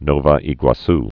(nôēgwä-s)